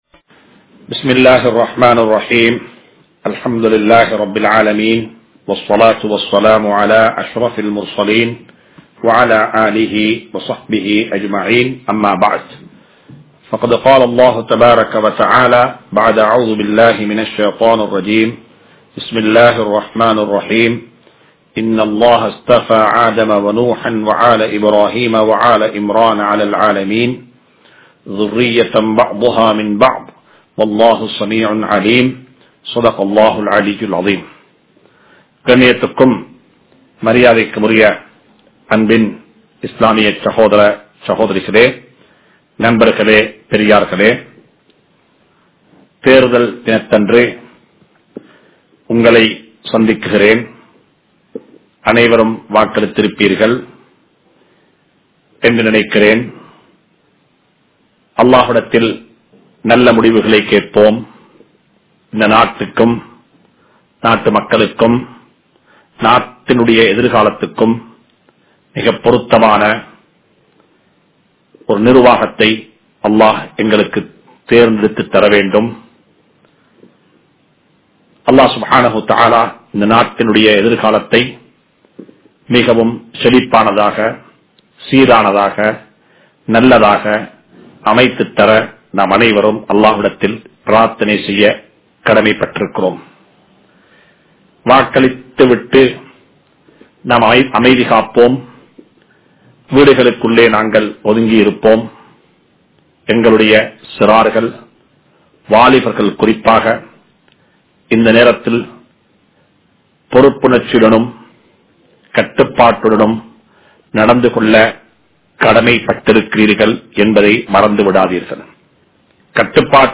Ibrahim(Alai)Avarhalin Ahlaaq(இப்றாஹீம்(அலை)அவர்களின் அஹ்லாக்) | Audio Bayans | All Ceylon Muslim Youth Community | Addalaichenai